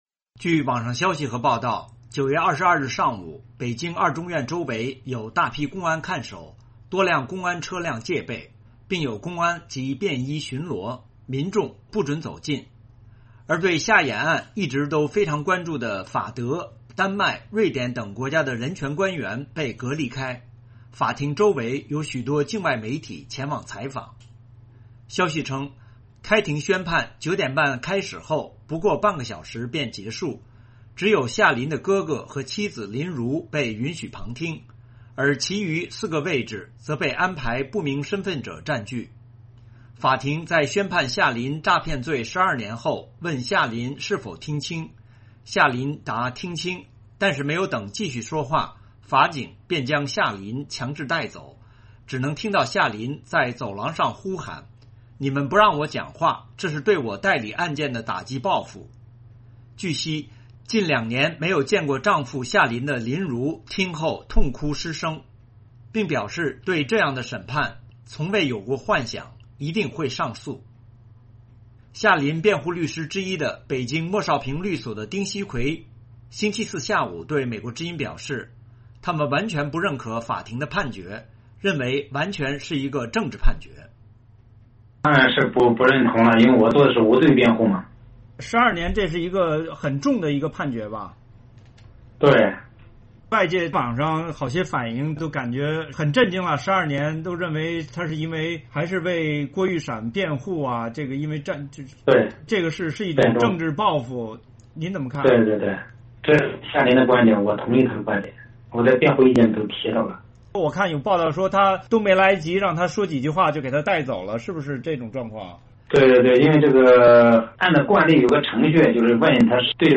美驻港总领事唐伟康在香港美国商会发表演说